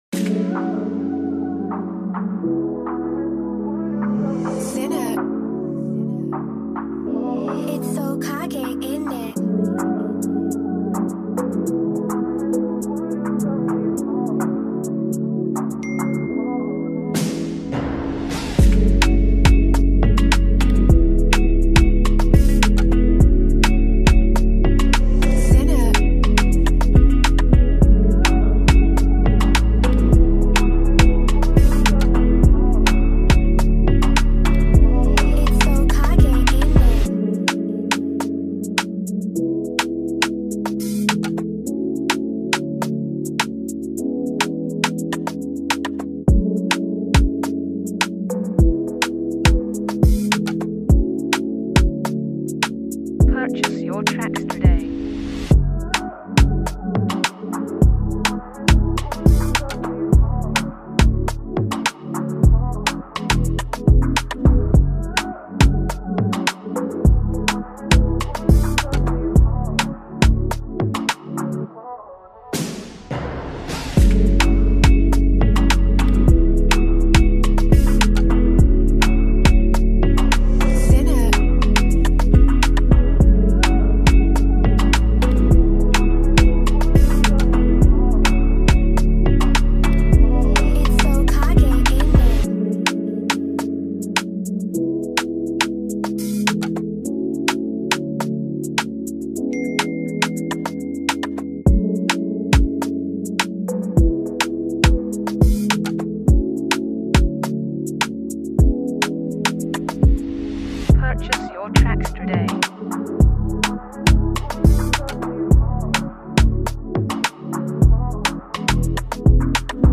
this track offers a distinctive blend of rhythm and melody.
The dynamic energy